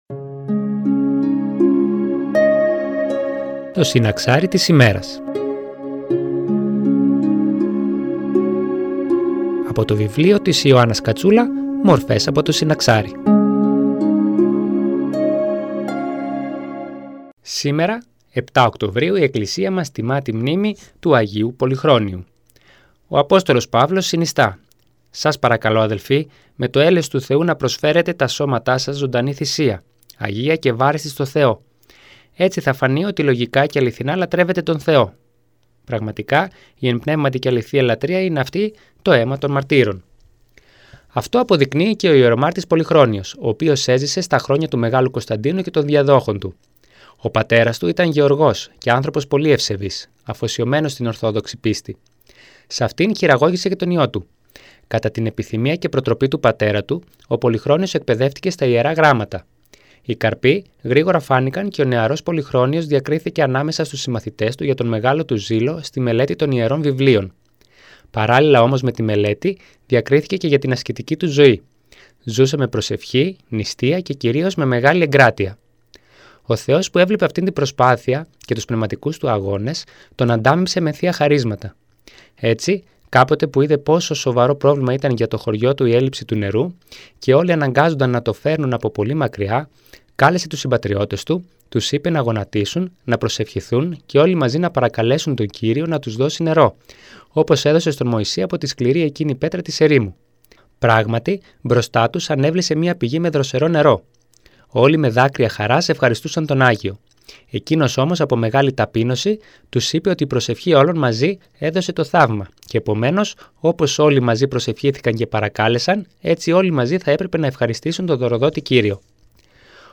Μια ένθετη εκπομπή που μεταδίδεται από Δευτέρα έως Παρασκευή στις 09:25 από την ΕΡΤ Φλώρινας.
Εκκλησιαστική εκπομπή